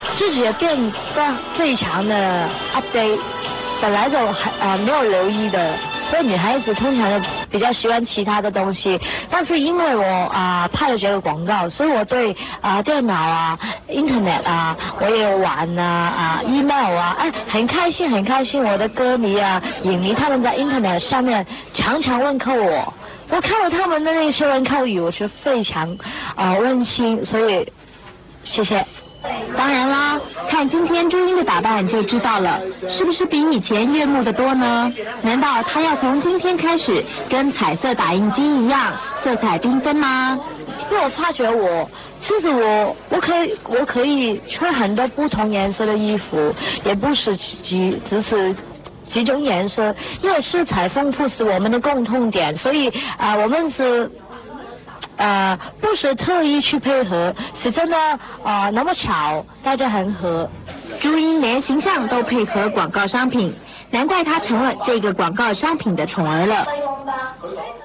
Athena on her collabration with Epson:[Listen to it]